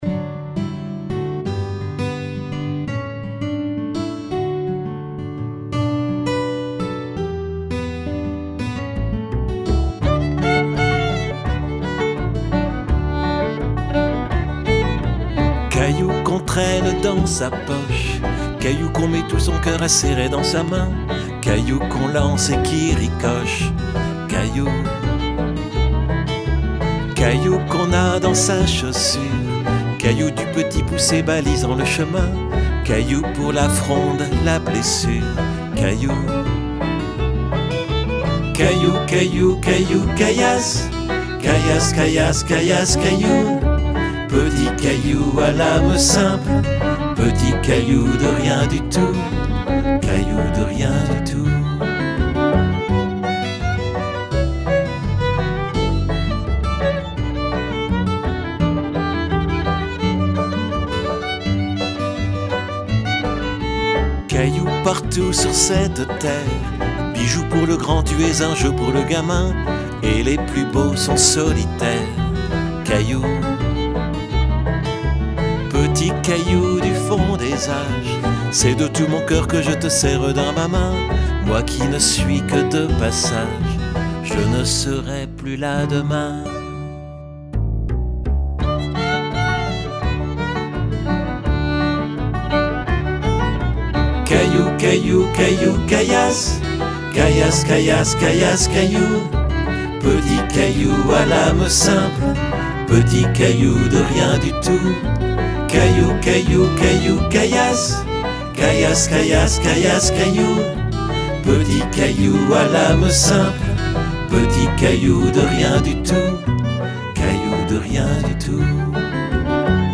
maquette de travail
chant